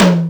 Royality free tom drum sound tuned to the F note. Loudest frequency: 861Hz
• Acoustic Tom Drum One Shot F Key 19.wav
acoustic-tom-drum-one-shot-f-key-19-1l6.wav